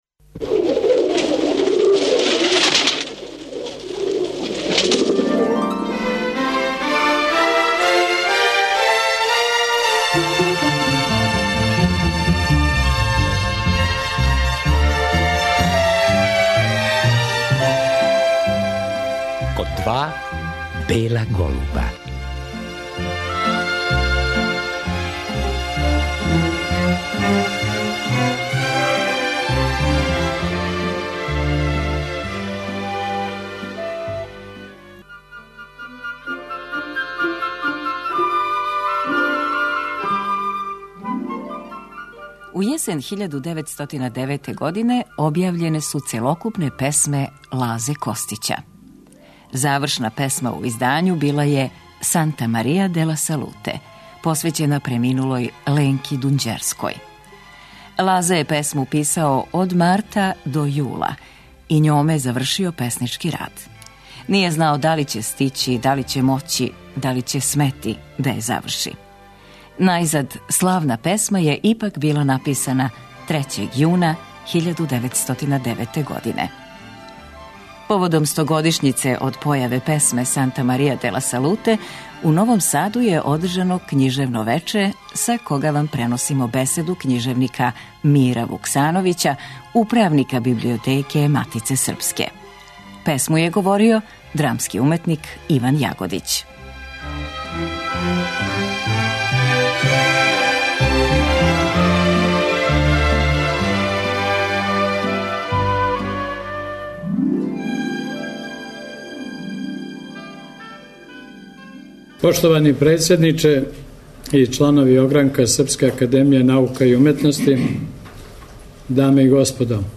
Поводом стогодишњице од појаве те песме, у Новом Саду је одржано књижевно вече 2009. године.